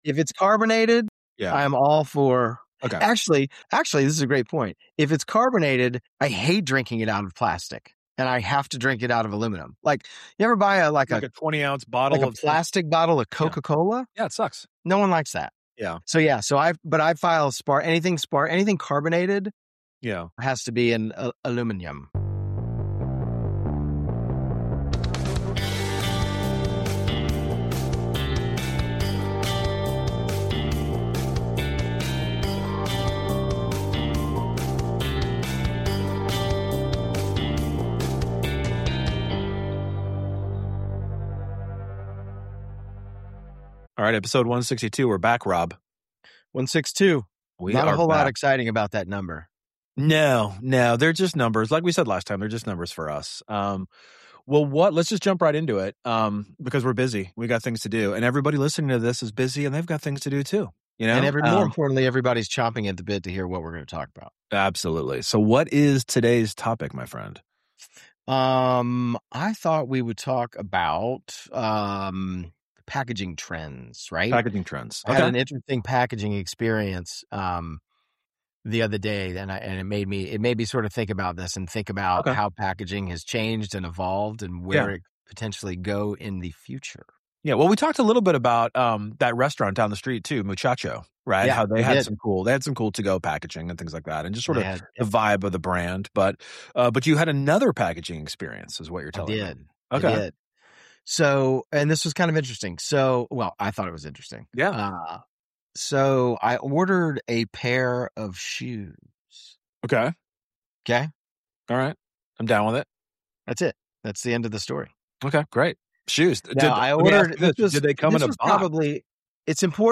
Tune in for a lively conversation that promises to both educate and entertain, offering a fresh take on the art of visual communication in the digital age.